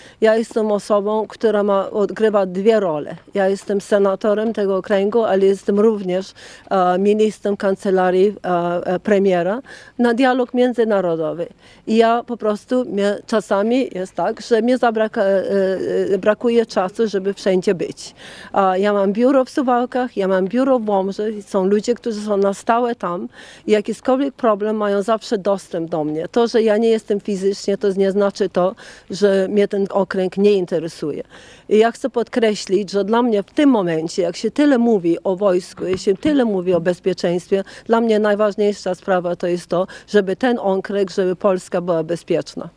W piątek (17.08) przy okazji wizyty polonijnej młodzieży z Wielkiej Brytanii w wigierskim klasztorze, Anna Maria Anders udzieliła krótkiego wywiadu Radiu 5, w którym odniosła się do stawianych jej zarzutów.